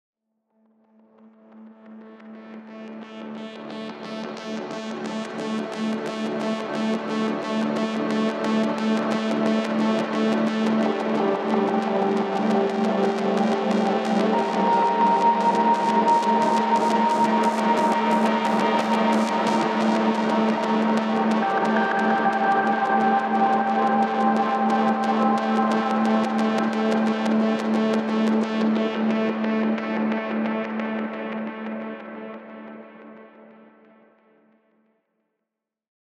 Синтезатор с кнопкой рандомизации